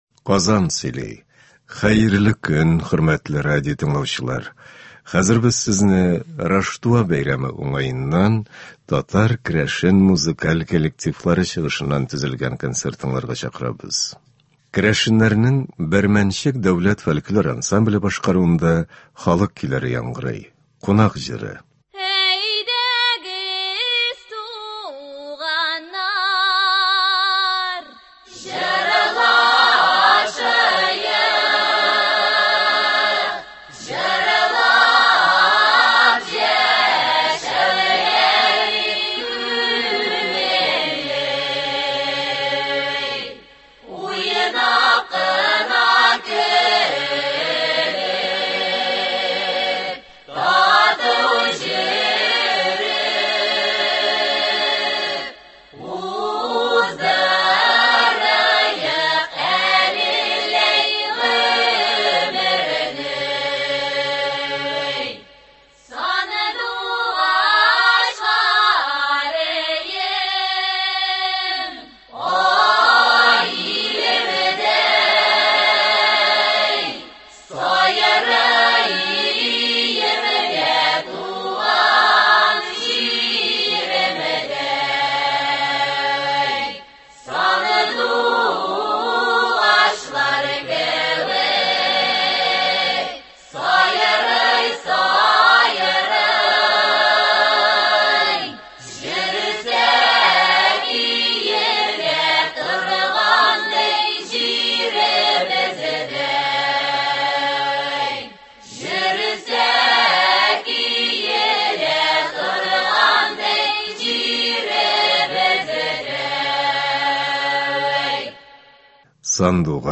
Татар-керәшен музыкаль коллективлары концерты.
Концерт (07.01.21)